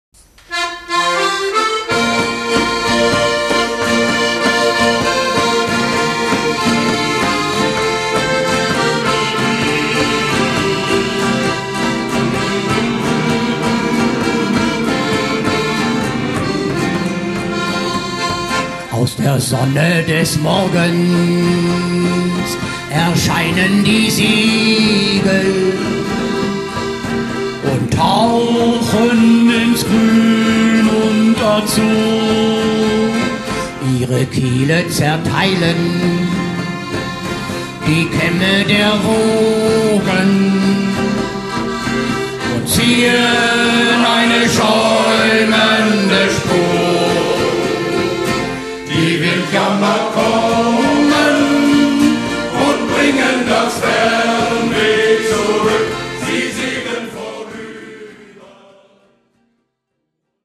Our Shanty-Chor